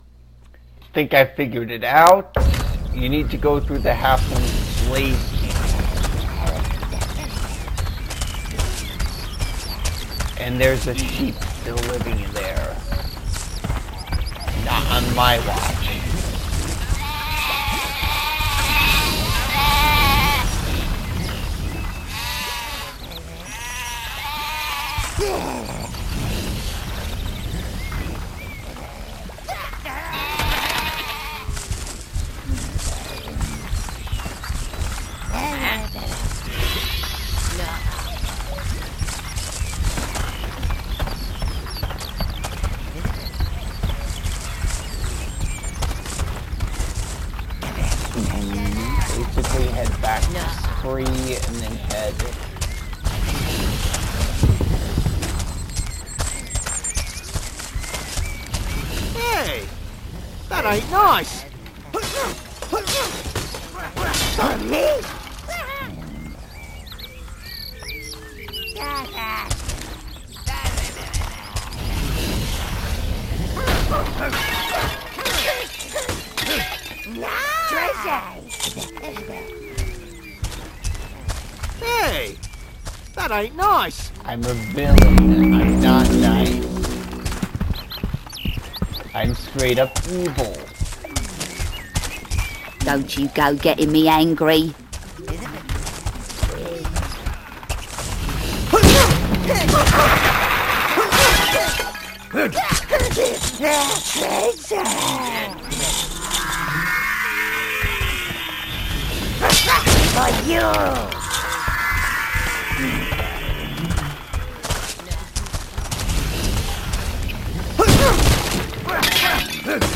I play Overlord with commentary